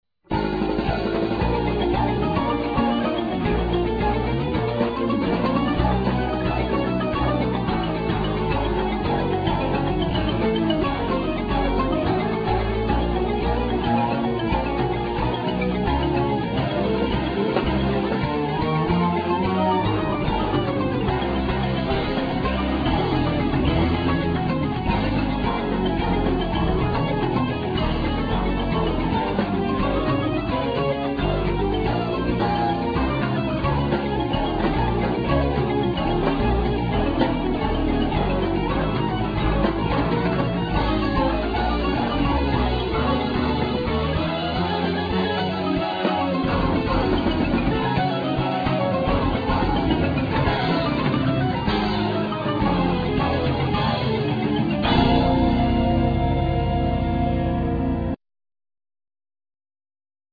Keyboards
Voices
Saxophones
Recorder flute